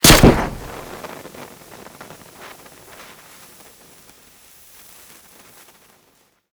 Incendiary_Near_04.ogg